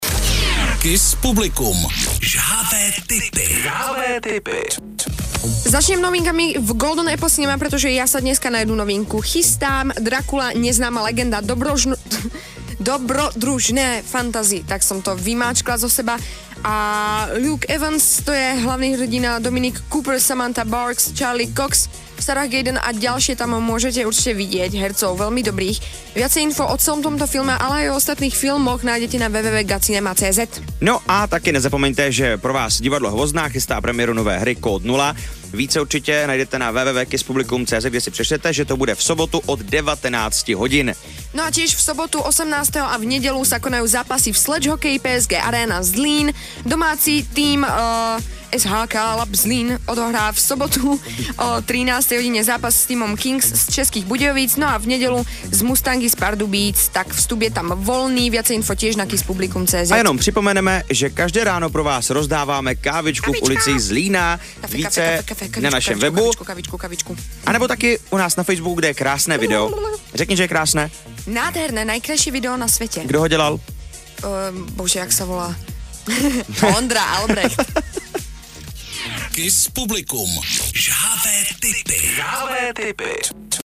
Upoutávky vysílané rádiem Kiss Publikum od úterý 14.10. na zápasy ČSHL